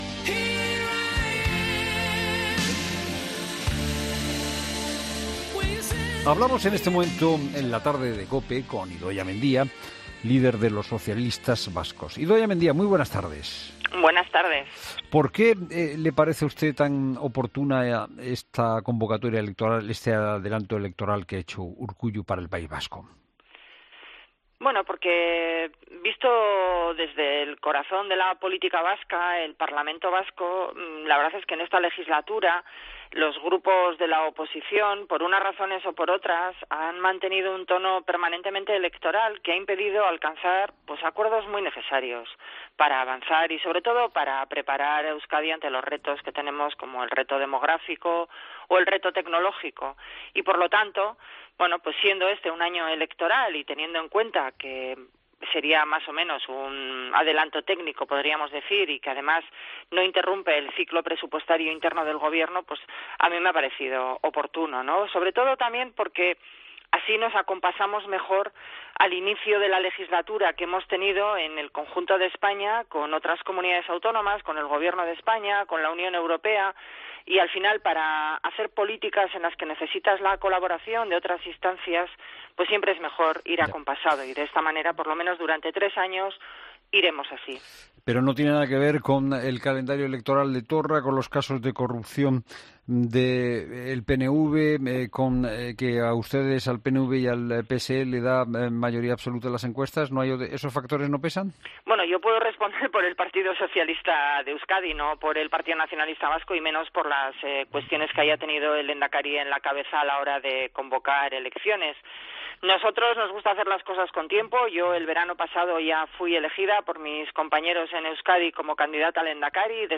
La secretaria general de los socialistas vascos ha sido entrevistada